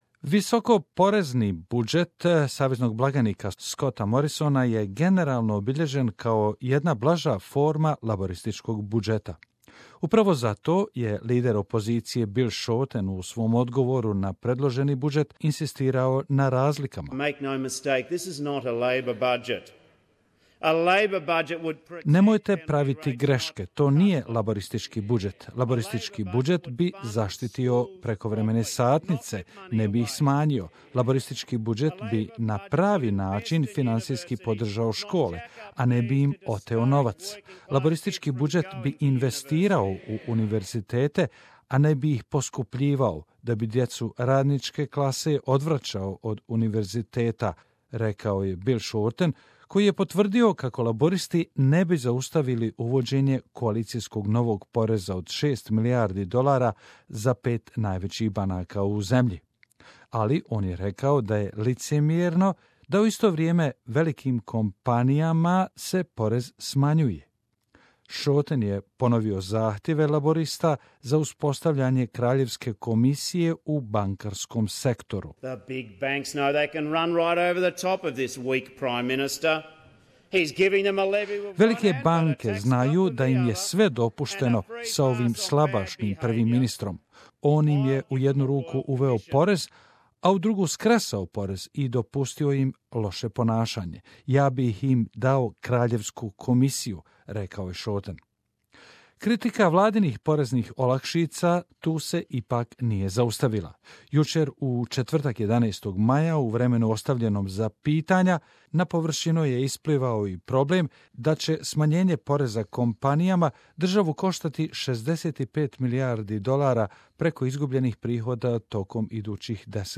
Bill Shorten delivers Budget reply speech